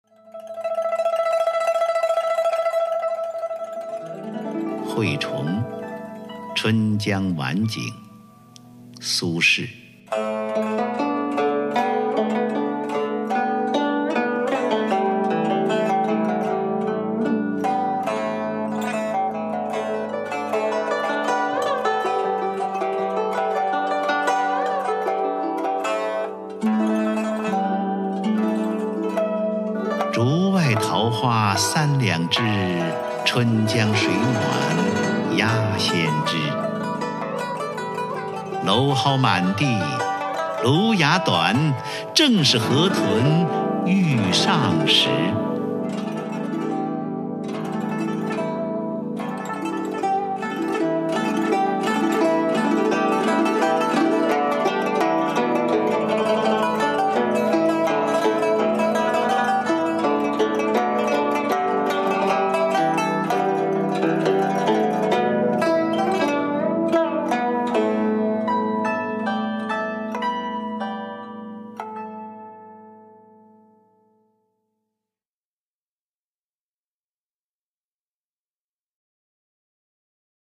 [宋代诗词诵读]苏轼-惠崇《春江晚景》 宋词朗诵